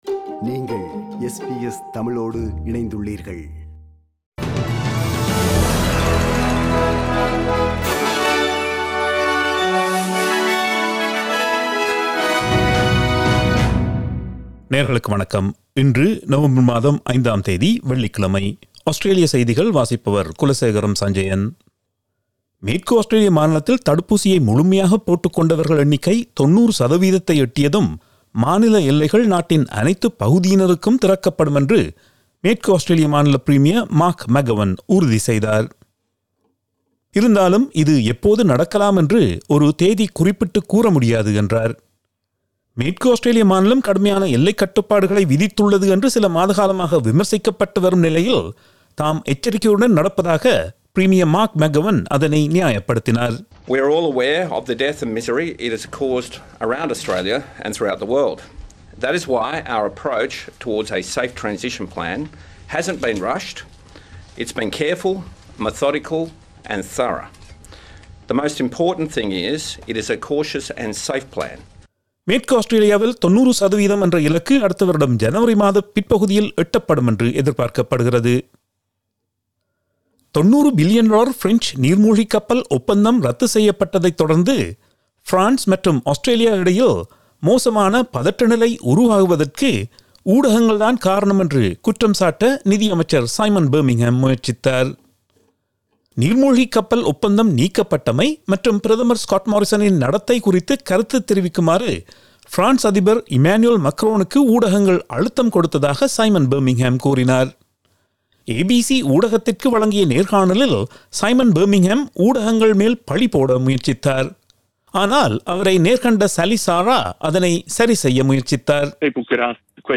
Australian news bulletin for Friday 05 November 2021.